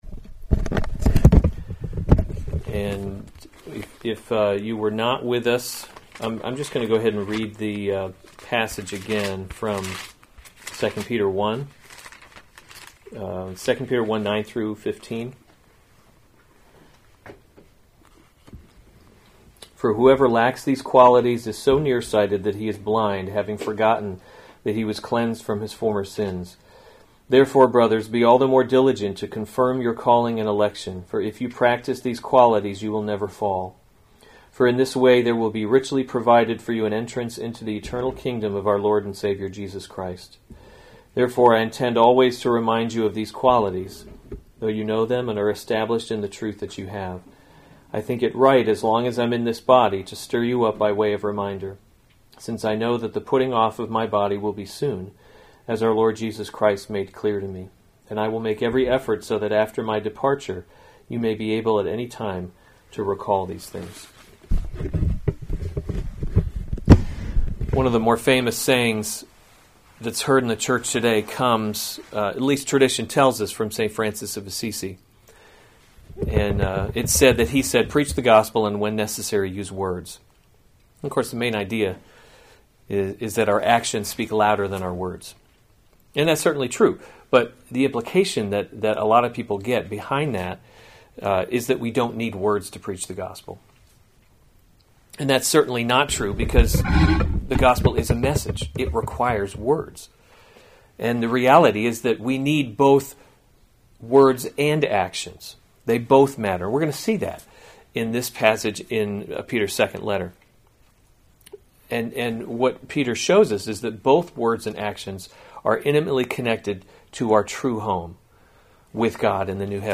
March 21, 2020 2 Peter – Covenant Living series Weekly Sunday Service Save/Download this sermon 2 Peter 1:9-15 Other sermons from 2 Peter 9 For whoever lacks these qualities is so […]